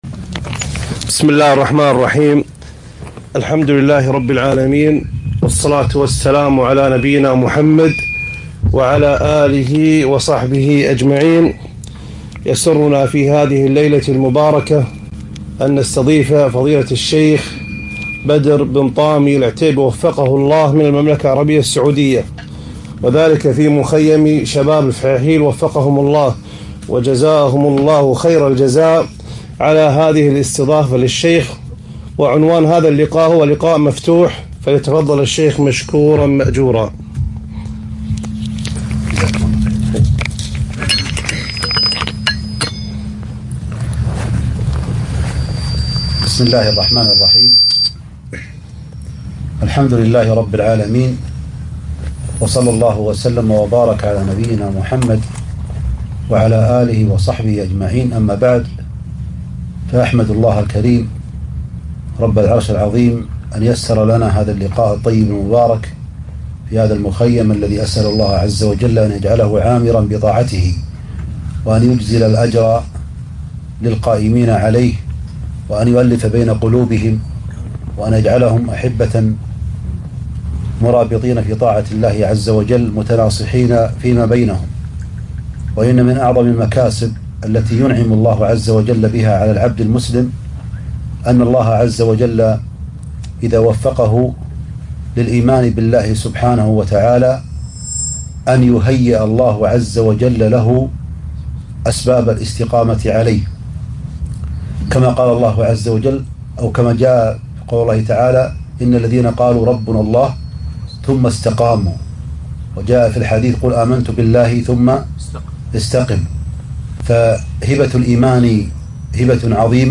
لقاء مفتوح بمخيم شباب الفحيحيل ( الكويت )